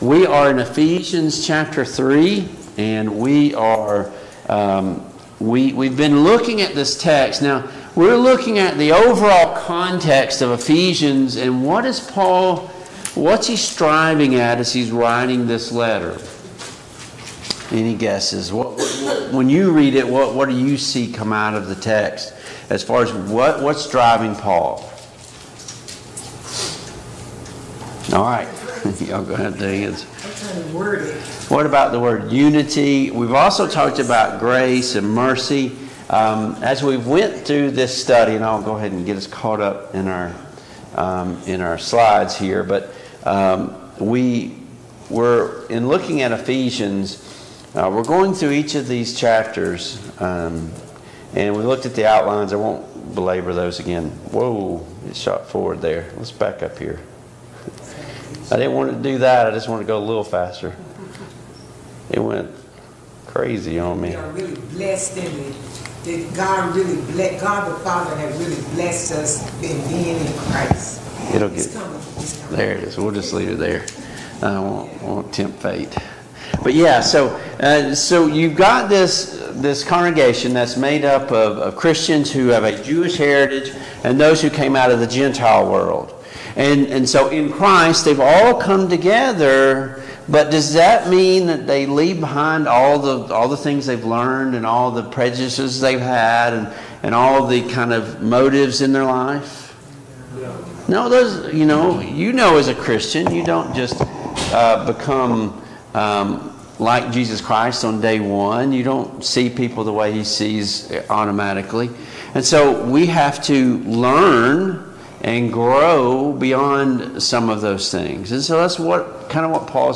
Passage: Ephesians 3:14-21 Service Type: Mid-Week Bible Study